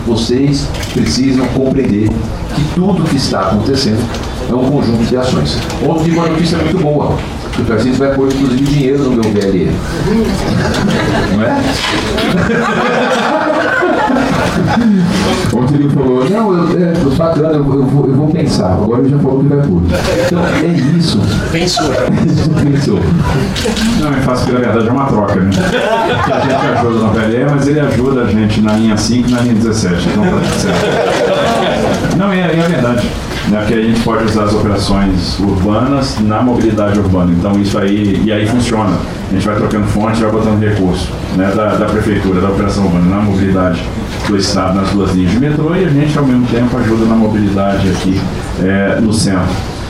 Declaração do prefeito foi ao lado de governador na apresentação do Boulevard São João.
Foi para todo mundo ouvir, ao lado do governador Tarcísio de Freitas, na tarde desta quinta-feira, 23 de abril de 2026, durante a entrevista coletiva de apresentação do projeto Boulevard São João, que está inserido no contexto dos planos da gestão pública para revitalizar a região central da cidade de São Paulo, há décadas marcada por degradação urbana e violência.